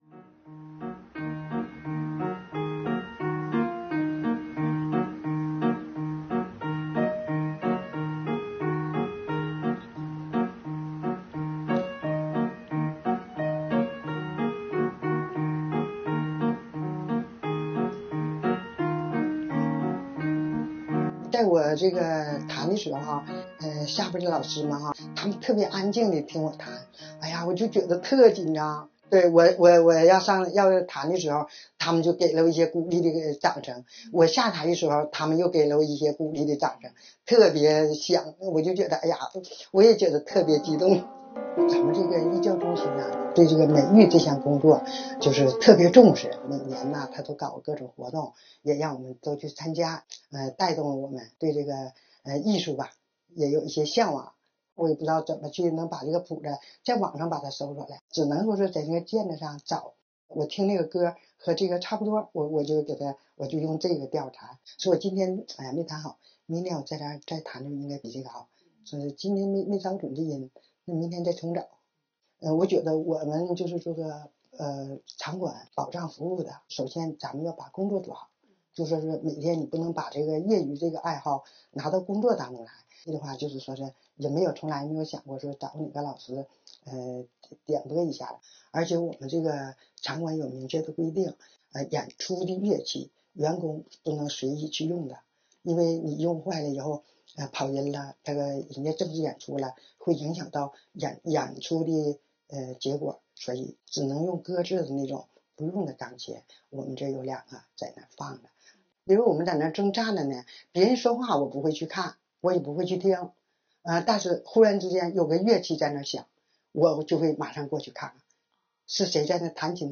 在舞台上认真弹奏
她用黑白键